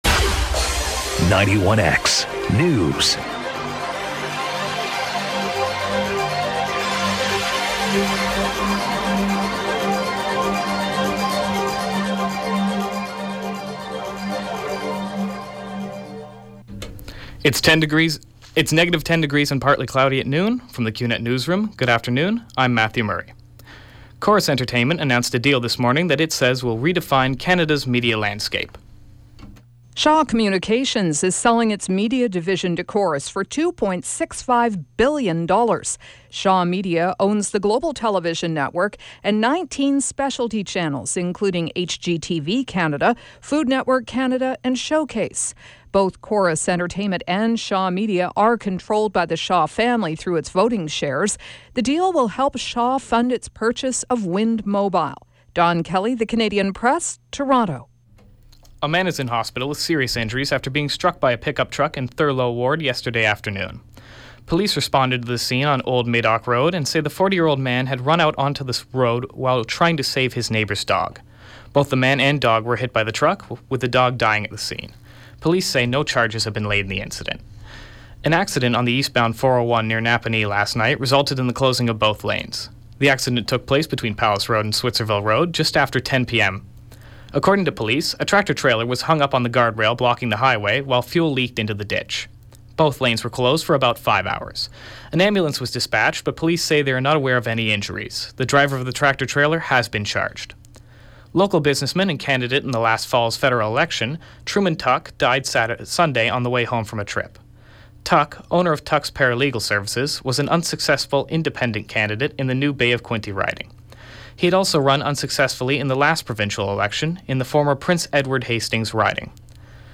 91X newscast – Wednesday, Jan. 13, 2016 – 12 p.m.